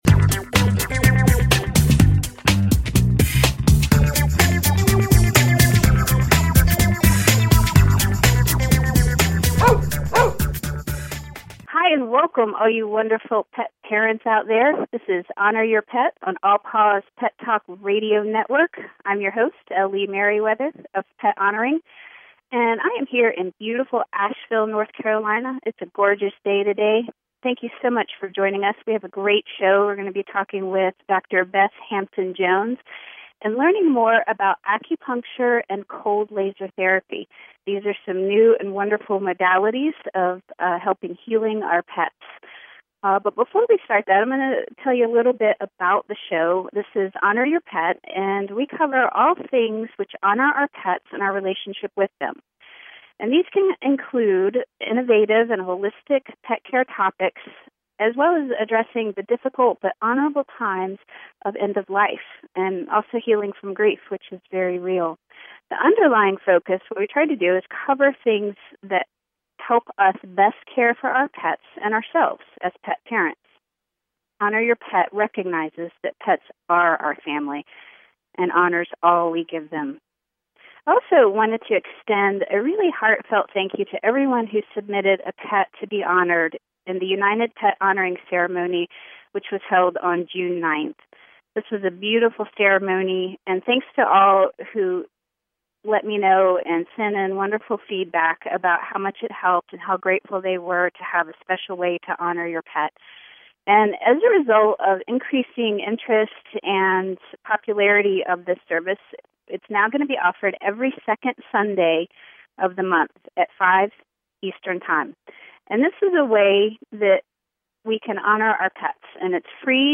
Honor Your Pet is a talk radio show covering all things which honor our pets and our relationship with them. These include innovative and holistic pet care topics as well as addressing the difficult, but honorable end-of-life times and healing grief from pet loss. The underlying focus is how we can best care for our pets and ourselves as pet parents.